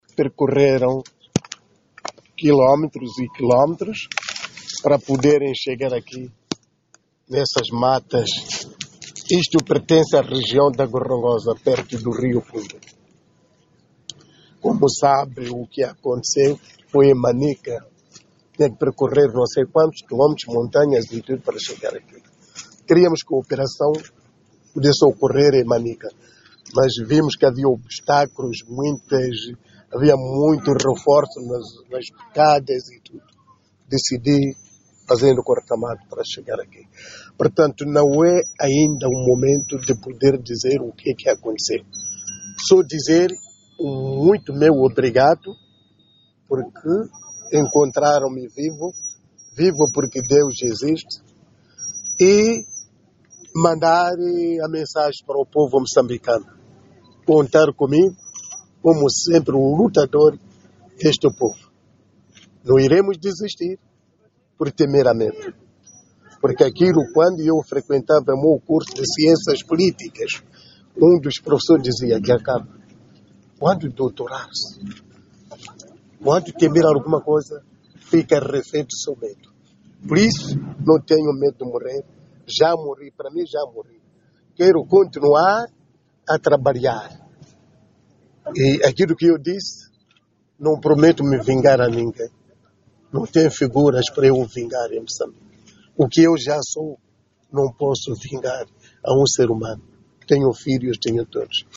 VOA assistiu encontro do líder da Renamo com observadores e personalidades.
Ouça as primeiras palavras de Afonso Dhlakama: